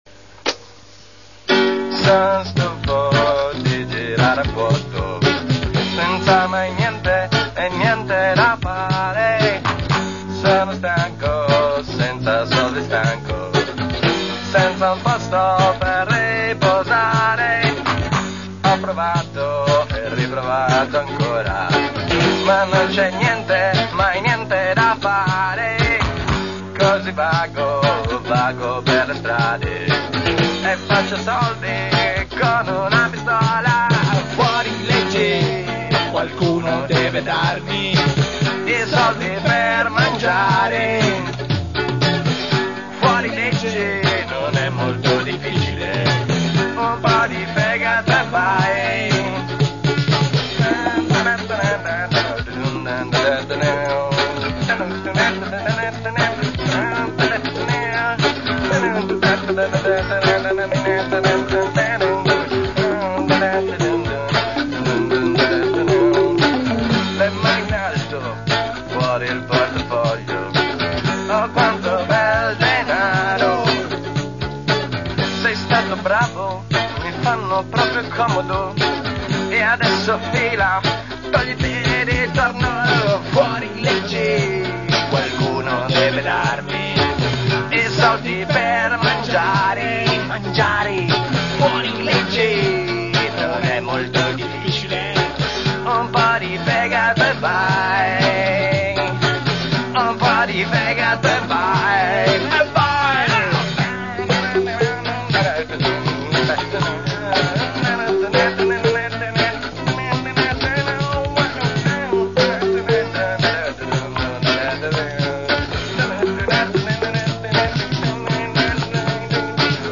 voce, chitarra
voce, percussioni, batteria
Registrazione elaborata separatamente dagli autori nel 1992